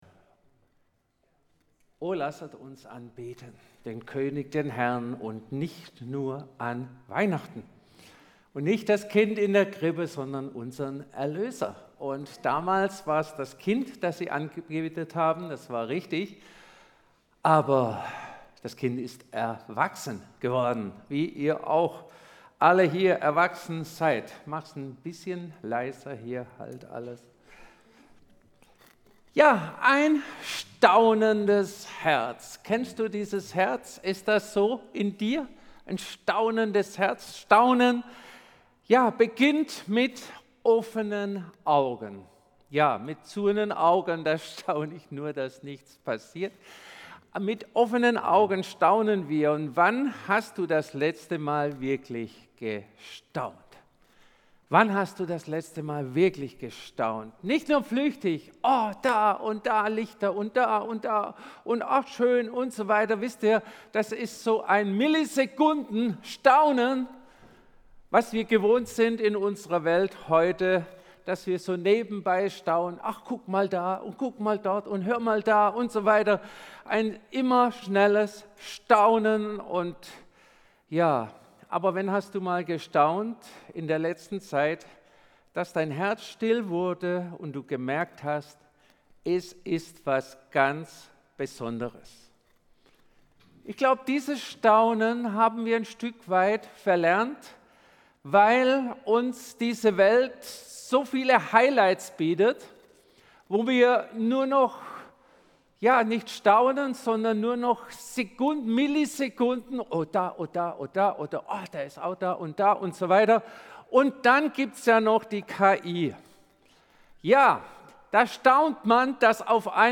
Diese Weihnachtspredigt zeigt, wie Staunen Glauben neu belebt, Herzen öffnet und Lob selbst in schwierigen Zeiten freisetzt. Lass dich von Maria, den Hirten und der Weihnachtsgeschichte neu lehren, Gott größer zu sehen als jede Situation.